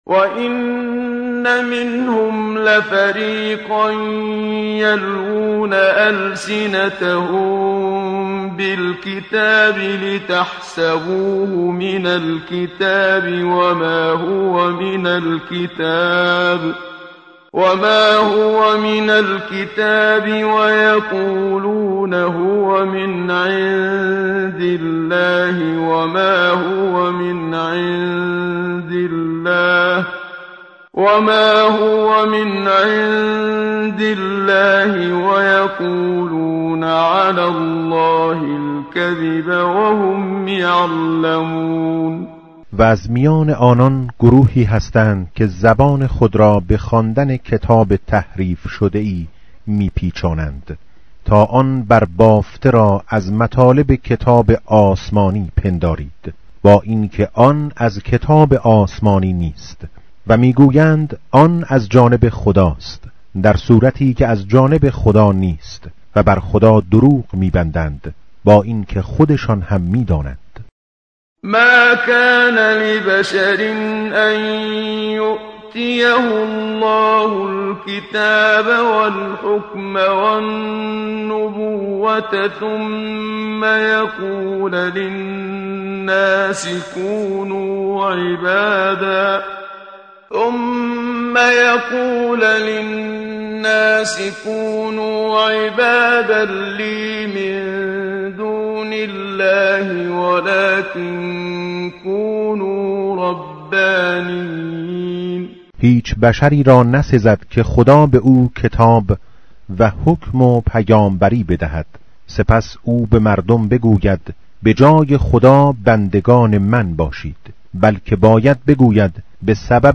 tartil_menshavi va tarjome_Page_060.mp3